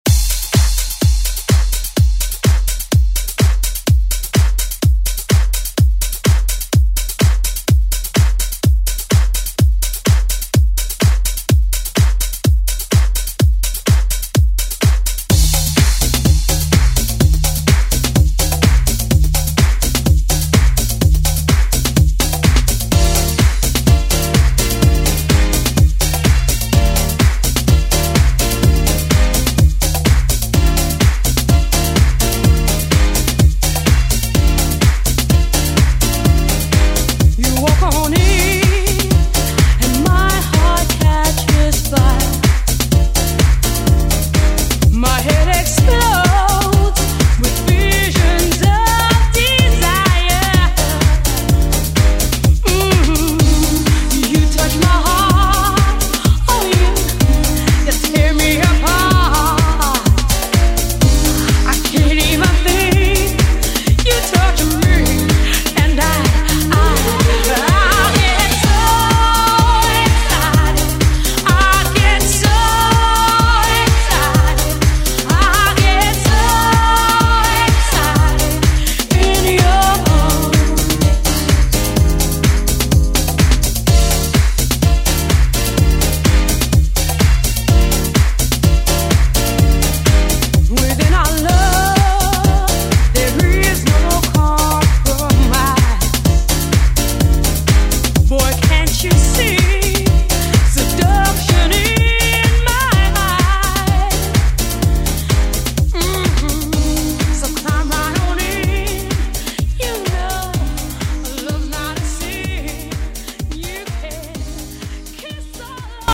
Electronic Pop Music
Genres: 80's , RE-DRUM , ROCK
BPM: 125